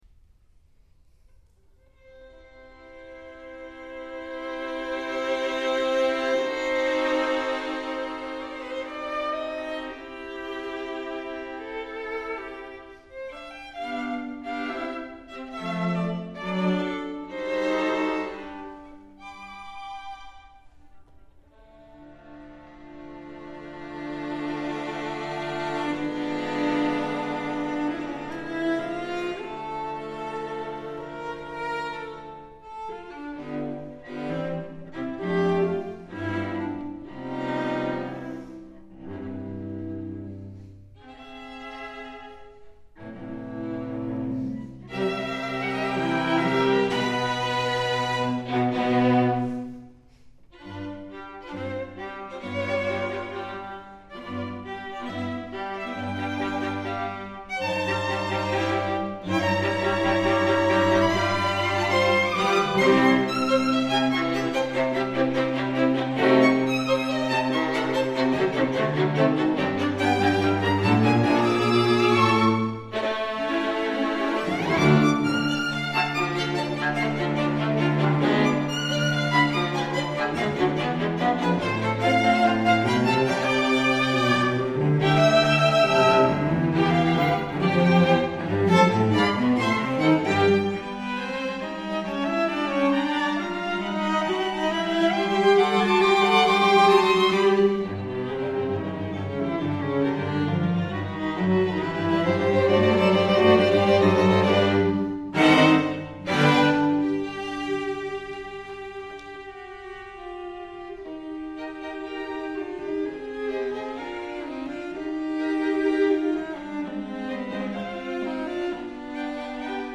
violins
viola